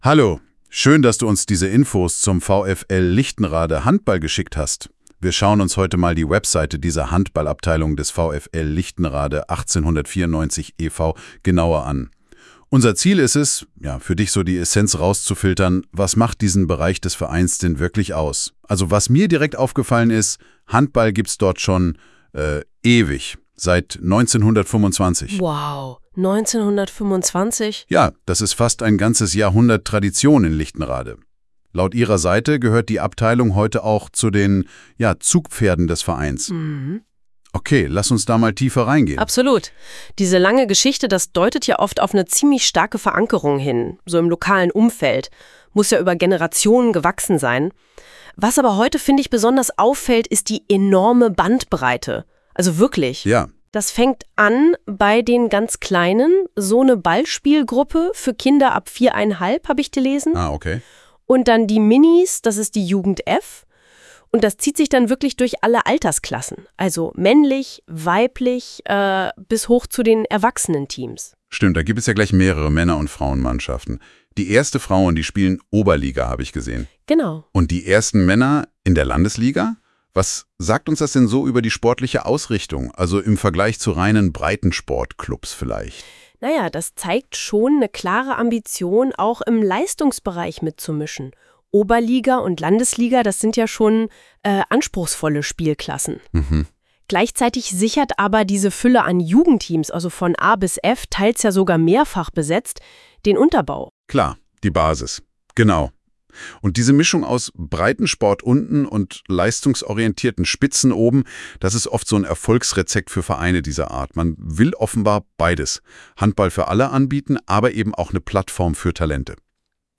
Dieser Podcast wurde von einer KI generiert und ist somit nicht ganz fehlerfrei.